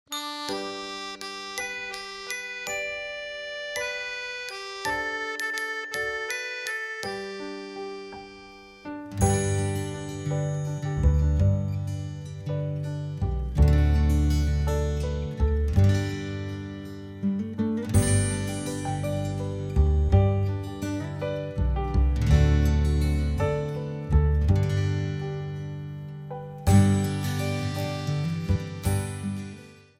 für eine oder zwei Sopranblockflöten
Besetzung: 1-2 Sopranblockflöten